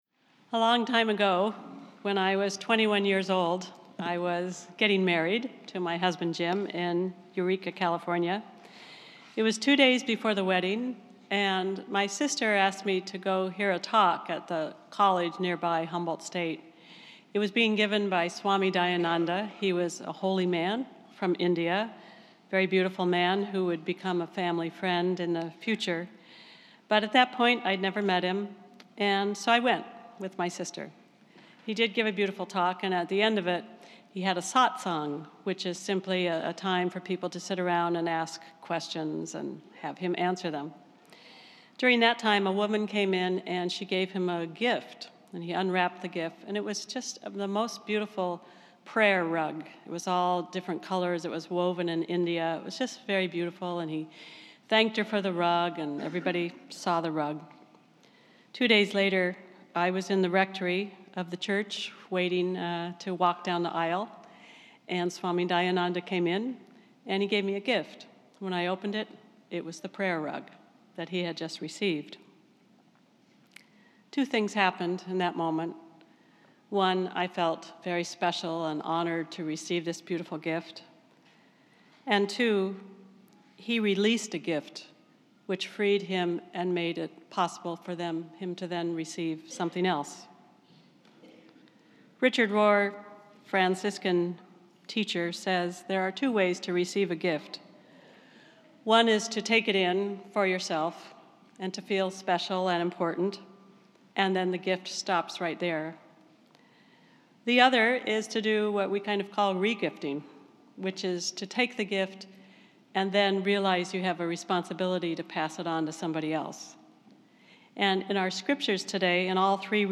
This is a Spiritus Christi Mass in Rochester, NY.